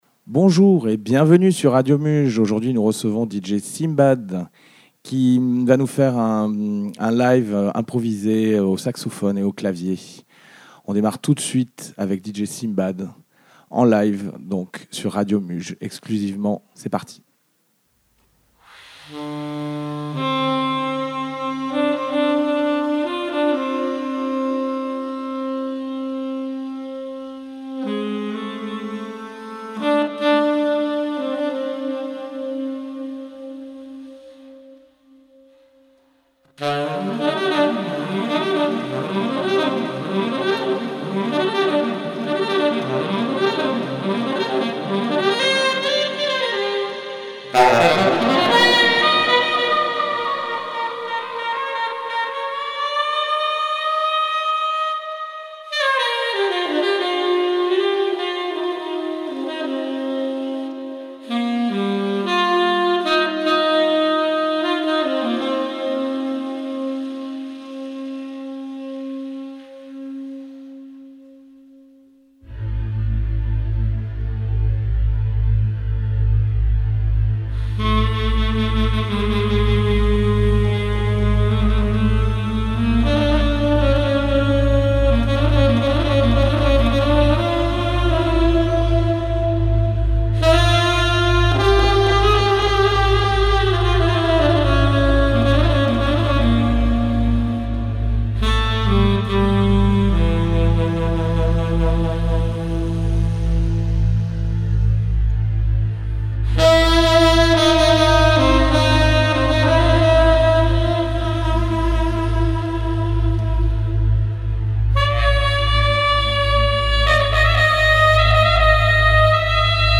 ITW & Impro
@ Radio Muge.